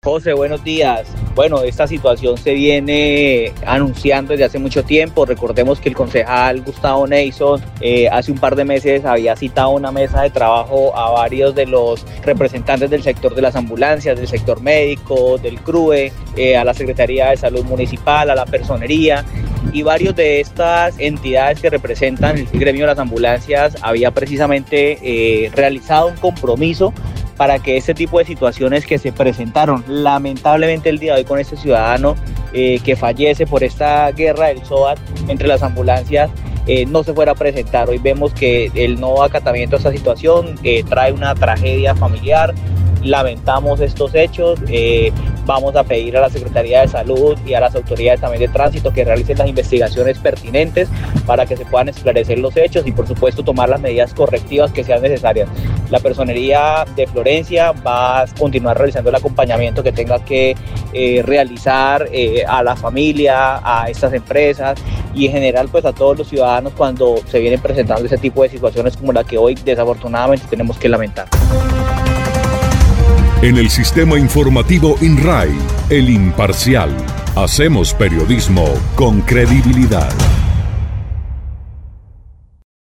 De acuerdo con Jorge Luis Lara Andrade, personero del municipio, lo anterior dejó en evidencia el no acatamiento de compromisos adquiridos semanas atrás por representantes de las empresas de ambulancias para evitar lo sucedido, por ello, anunció investigaciones de fondo.